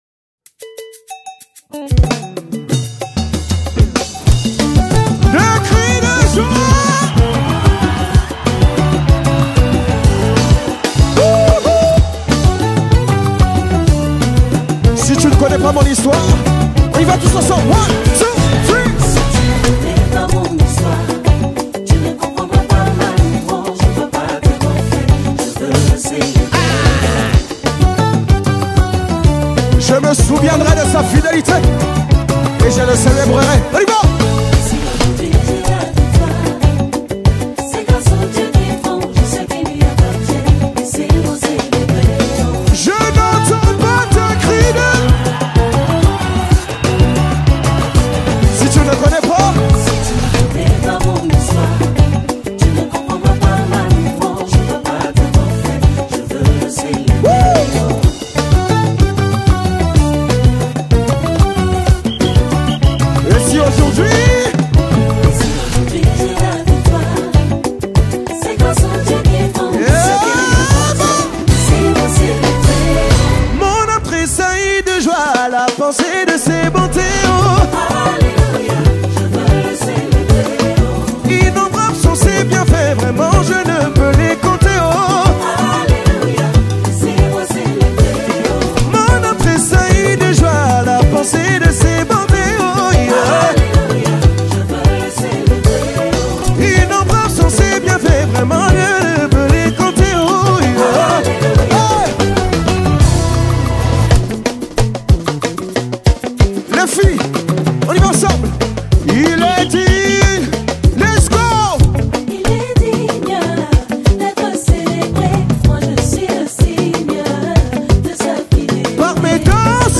Genre: Gospel/Christian